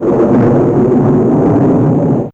Thndr1.wav